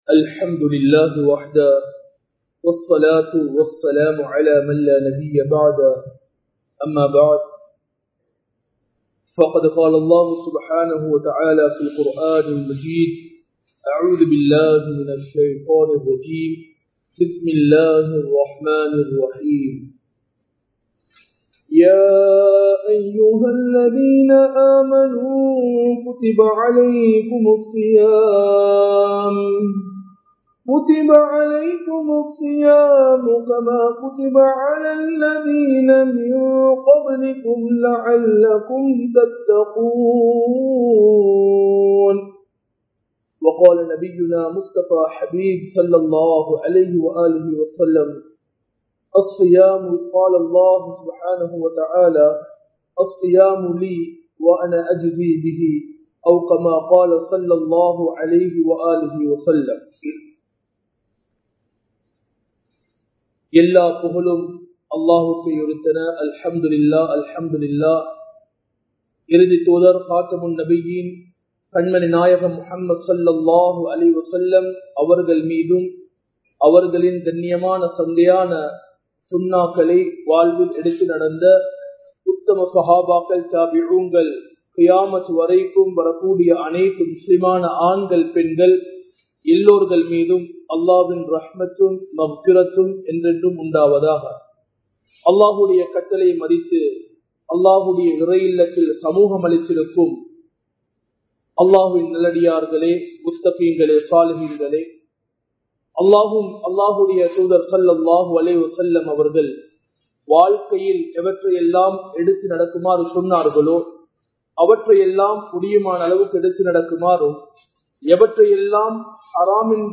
ரமழானும் 07 வகையான அமல்களும் | Audio Bayans | All Ceylon Muslim Youth Community | Addalaichenai
Majma Ul Khairah Jumua Masjith (Nimal Road)